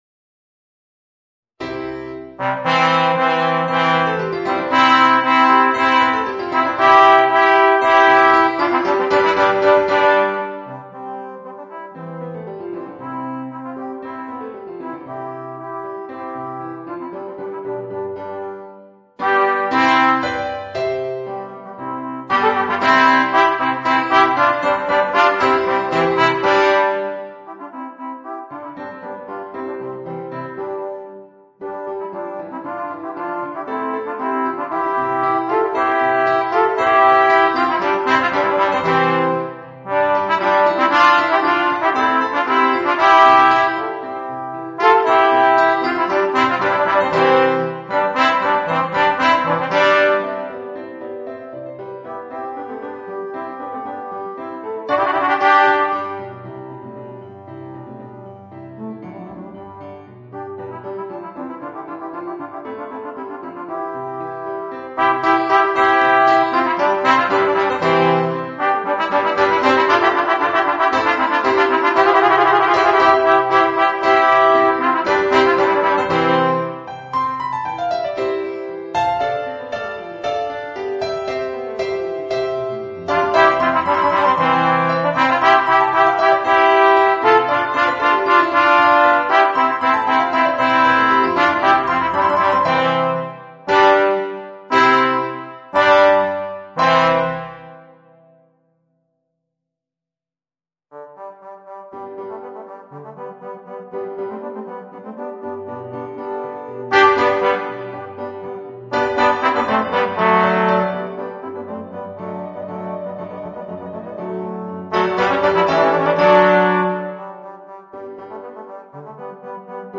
Voicing: Alto Trombone, Tenor Trombone and Piano